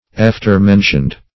Search Result for " after-mentioned" : The Collaborative International Dictionary of English v.0.48: After-mentioned \Aft"er-men`tioned\, a. Mentioned afterwards; as, persons after-mentioned (in a writing).